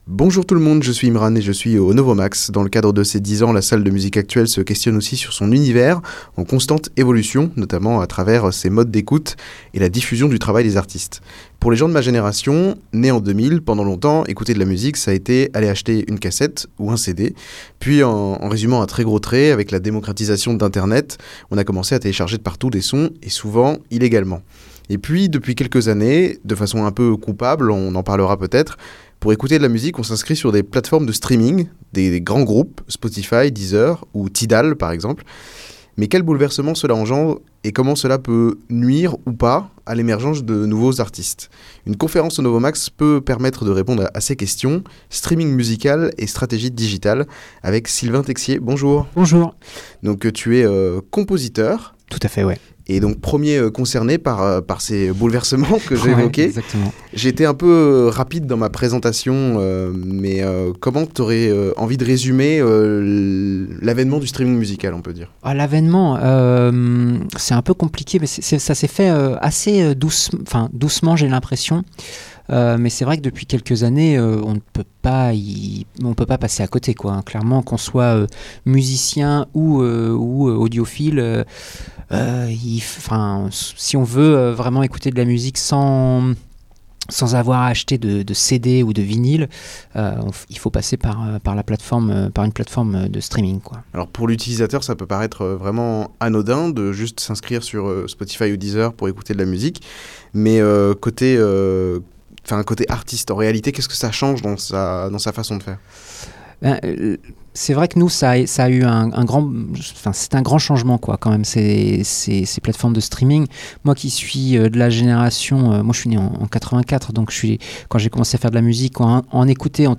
Une conférence au Novomax a permis aux apprentis musiciens de comprendre cette nouvelle donne